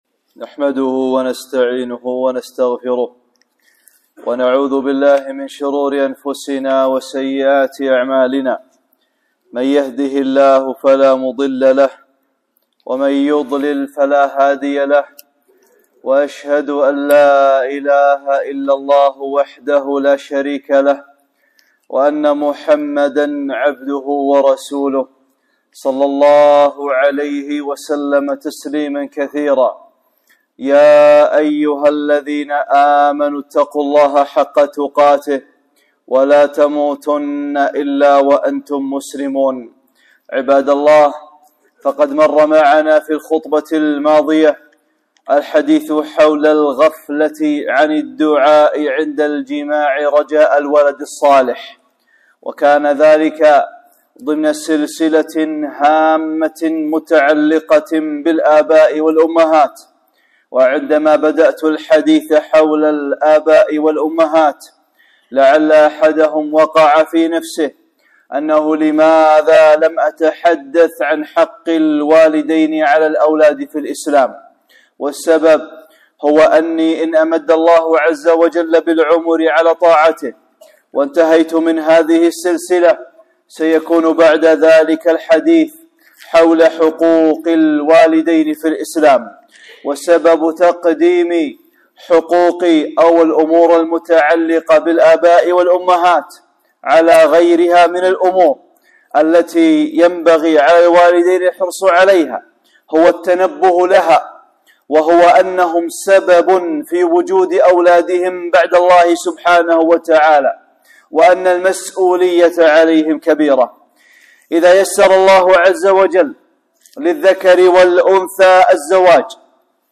(10) خطبة - نعمة المصاهرة والولد - أمور هامة متعلقة بالآباء والأمهات